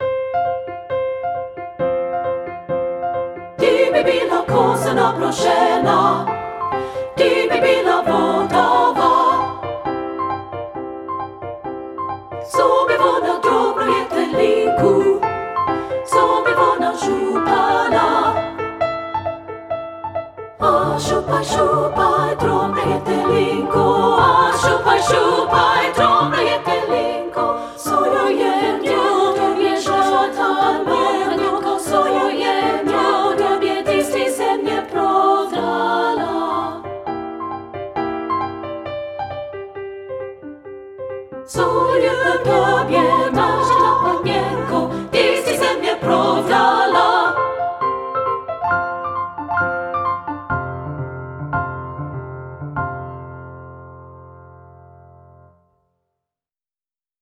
SSA Voices with Piano
• Soprano 1
• Alto
• Piano
Studio Recording
Ensemble: Treble Chorus
Key: F major
Tempo: Allegro (q = 134)
Accompanied: Accompanied Chorus